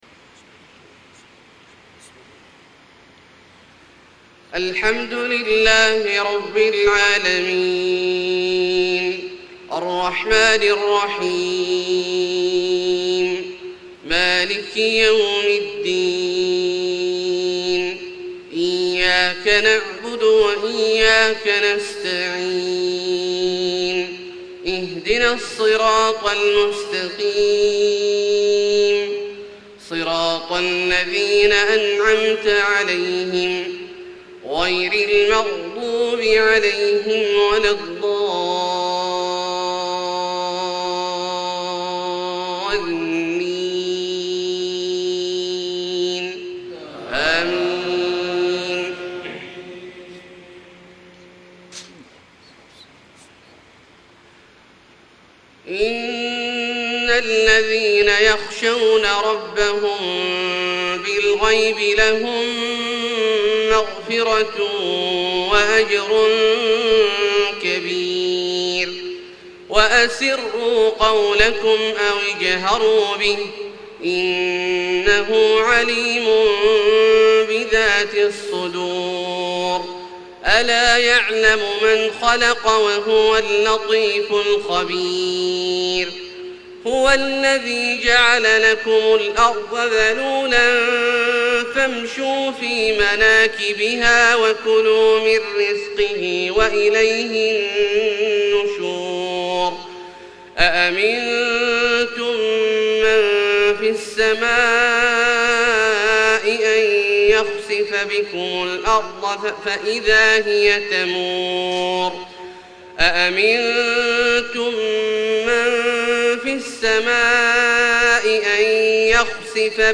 صلاة الفجر 6-8-1434 من سورة الملك > 1434 🕋 > الفروض - تلاوات الحرمين